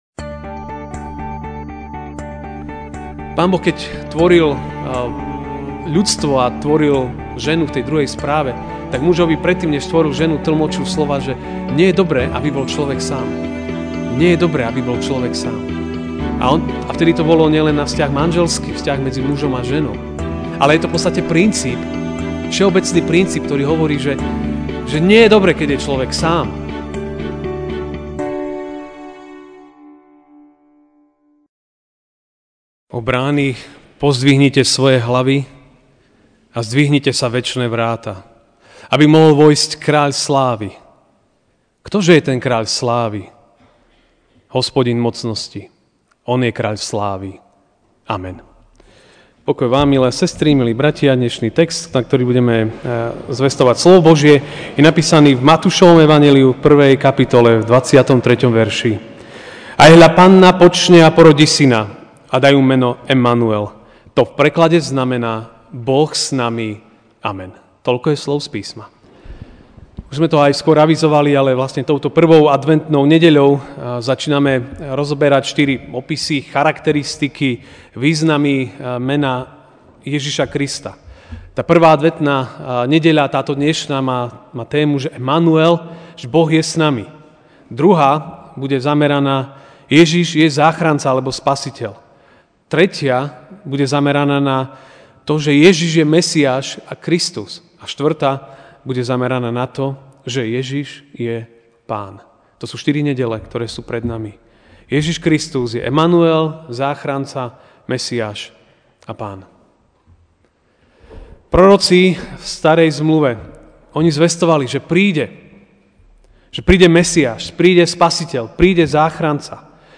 Ranná kázeň: Emanuel = Boh s nami (Mt 1, 23)Ajhľa, panna počne a porodí syna a dajú Mu meno Emanuel; to v preklade znamená: Boh s nami.